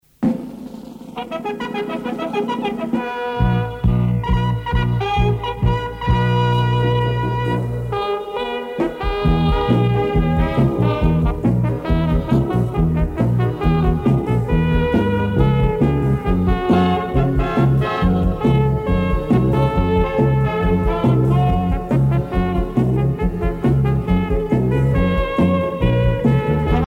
danse : jerk
Pièce musicale éditée